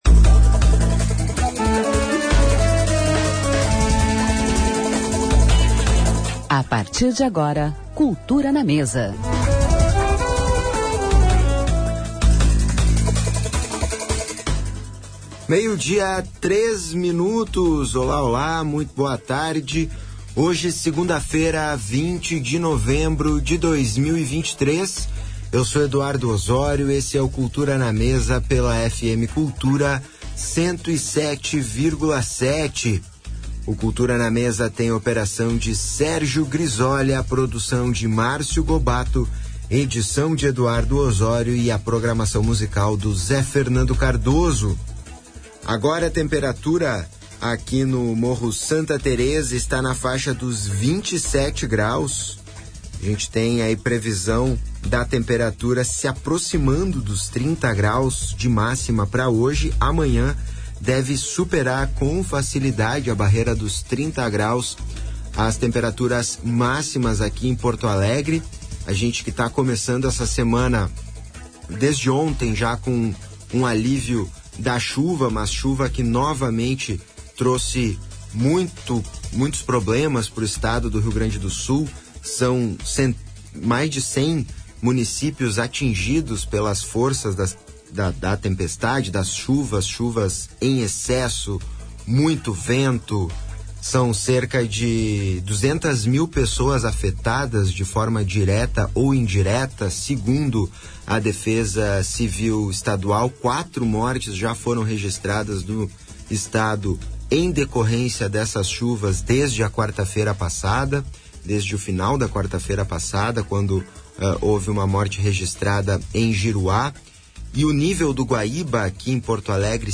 Entrevistas
com música ao vivo